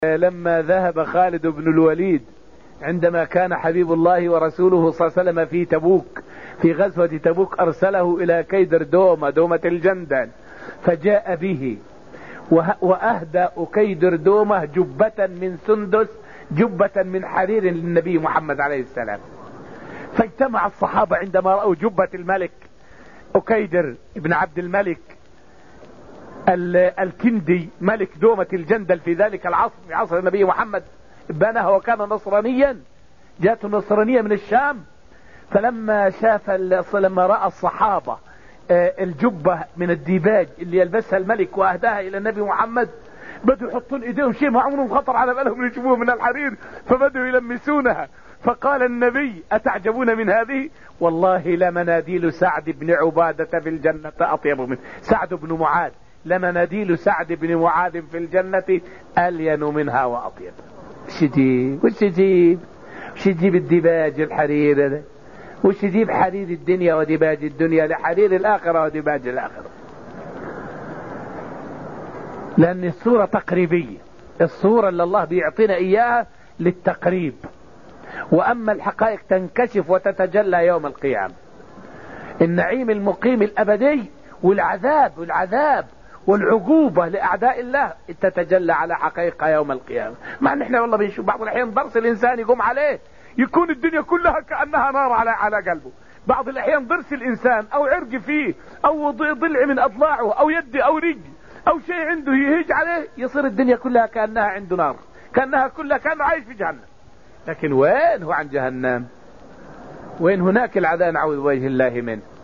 فائدة من الدرس الرابع من دروس تفسير سورة الطور والتي ألقيت في المسجد النبوي الشريف حول بيان أنه لا قياس بين نعيم الدنيا ونعيم الجنة.